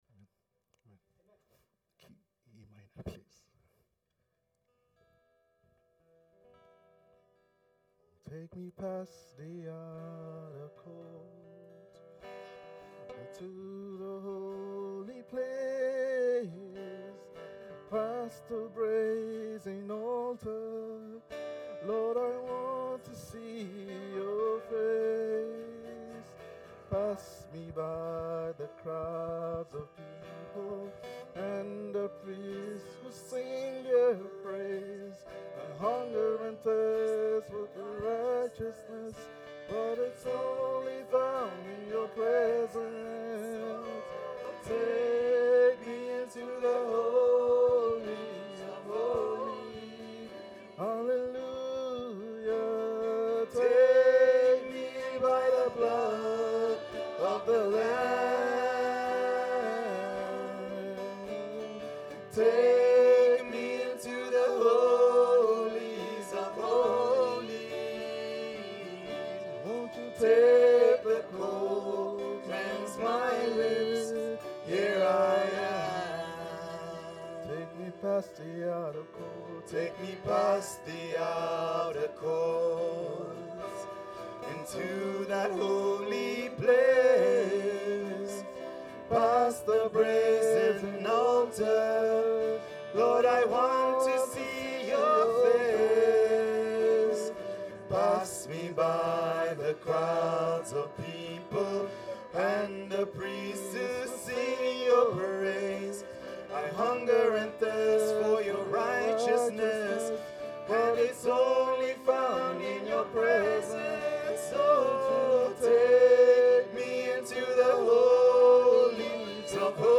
Sermons Series